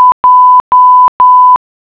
Nota.- Para escuchar el sonido, hacer clic en la clave morse correspondiente (el sonido se escuchará a una velocidad de 10 palabras/minuto).